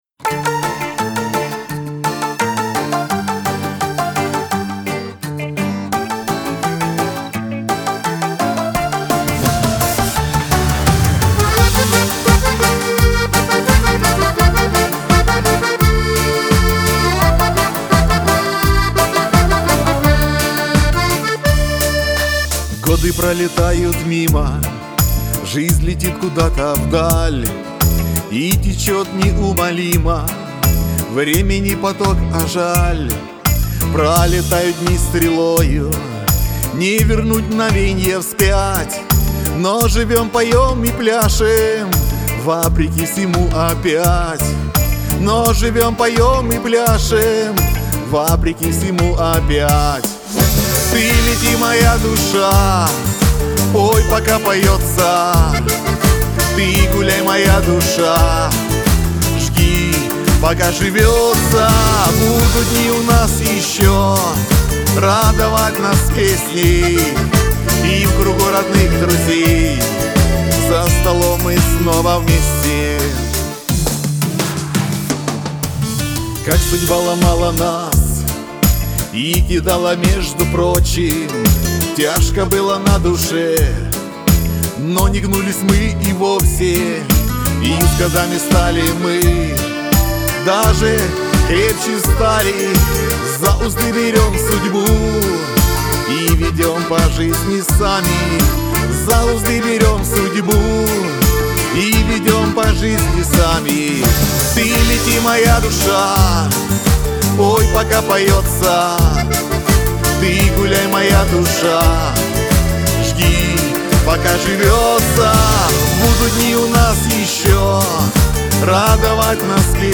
Лирика , диско
Шансон